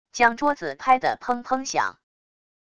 将桌子拍的砰砰响wav音频